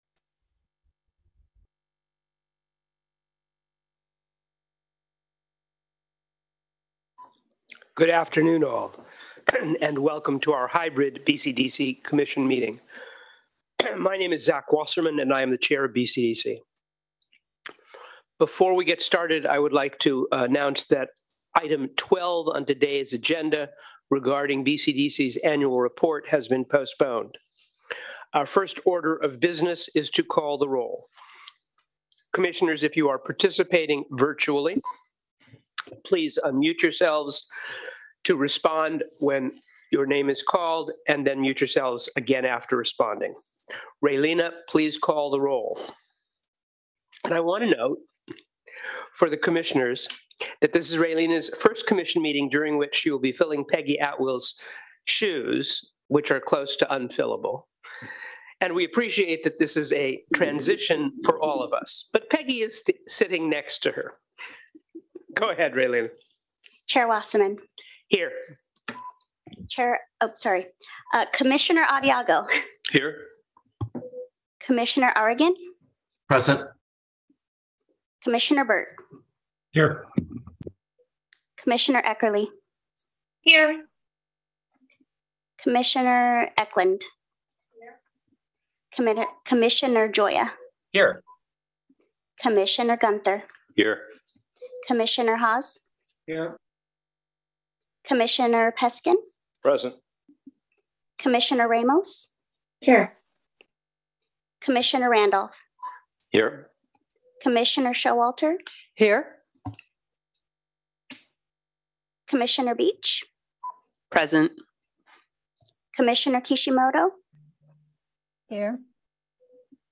May 4, 2023 Commission Meeting | SF Bay Conservation & Development